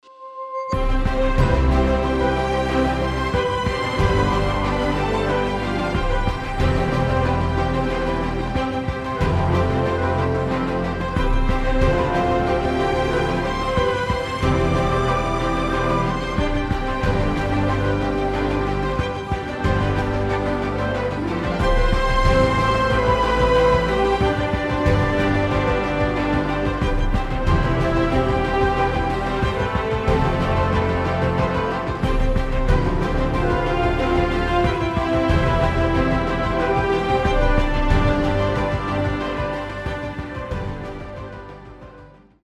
Рингтоны без слов
Инструментальные